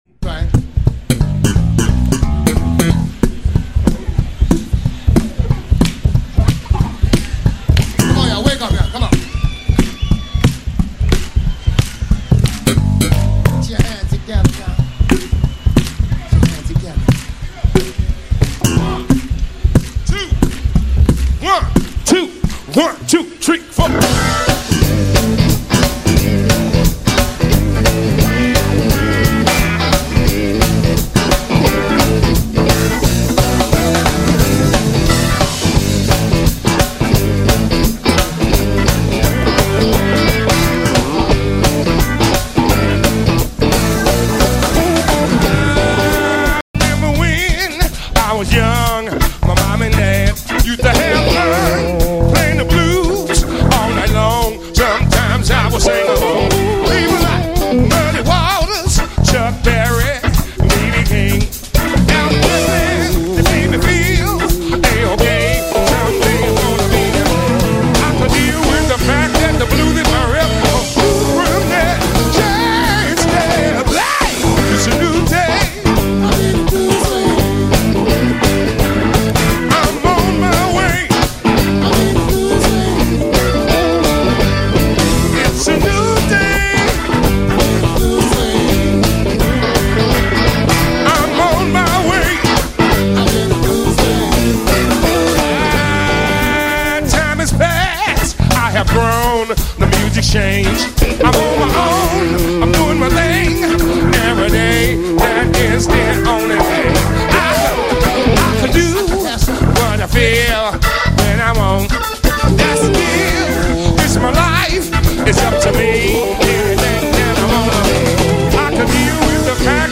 recorded at JazzFest 2013
via their gig at the 2013 JazzFest.